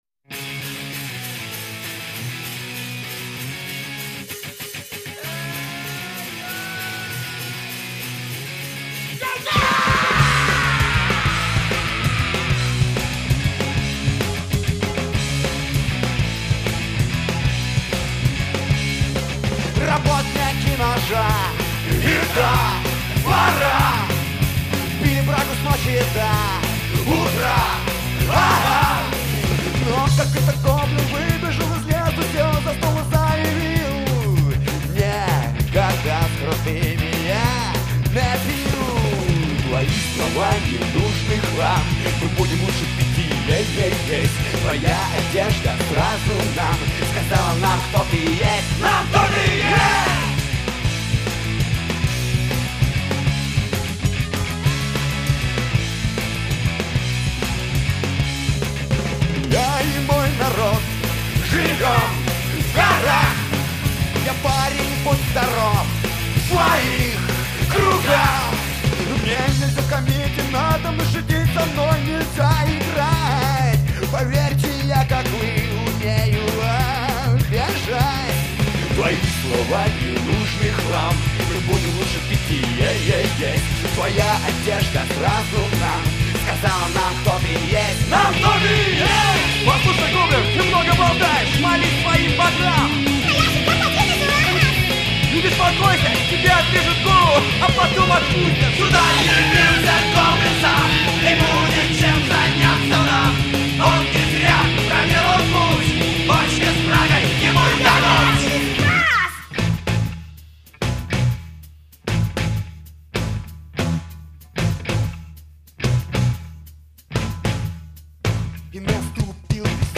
Категория: Рок